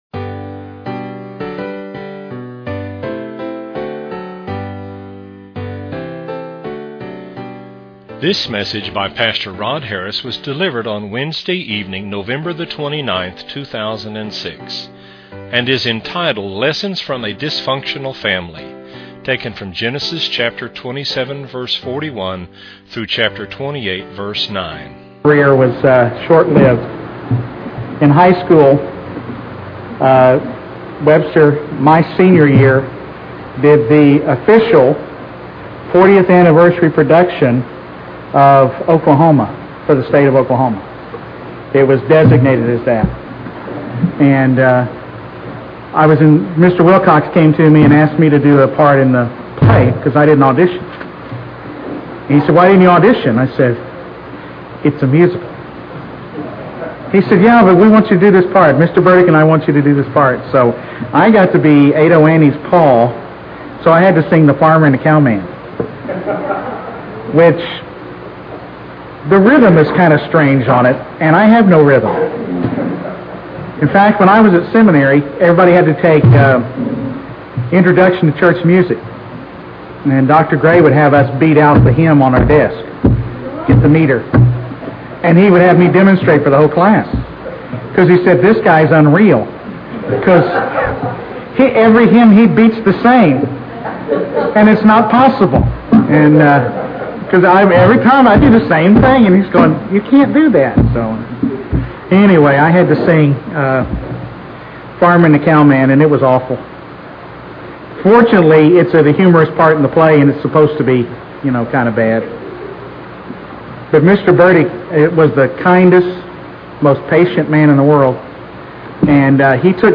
Wednesday evening Sermons - TBCTulsa - Page 12